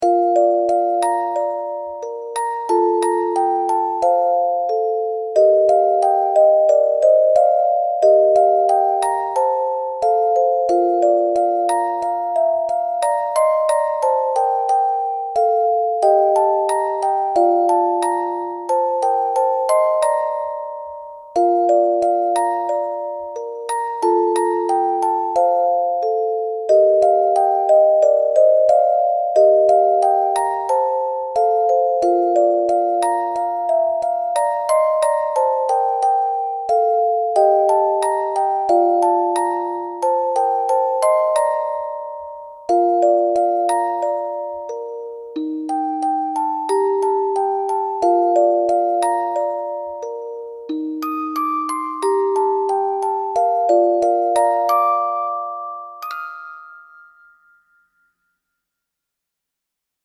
オリジナルのオルゴール曲を中心としたMP3を、無料のBGM素材として公開配布しています。
可愛い感じに仕上がりました。